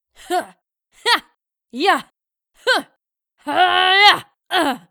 Female Warrior Grunts
Grunts HD Deep.mp3